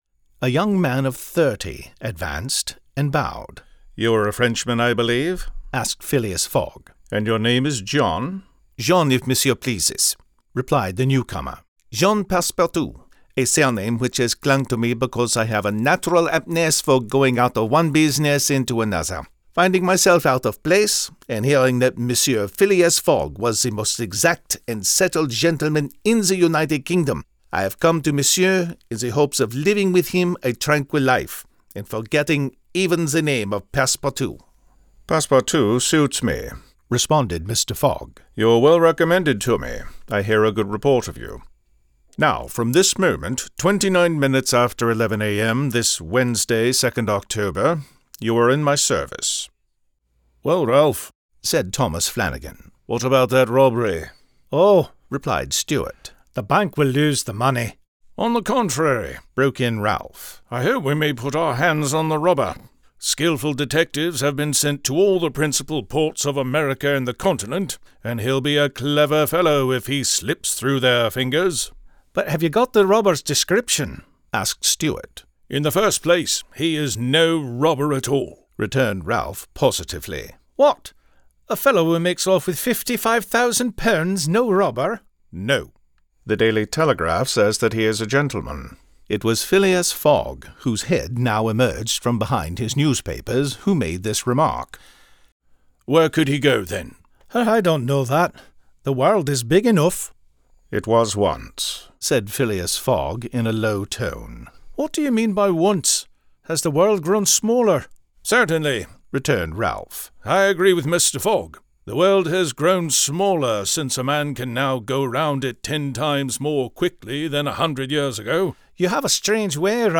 "Around the World in 80 Days" Audiobook Sampler
English (Standard), English (Southern US), English (British Isles)
ATWI80D Sample Demo_Final.mp3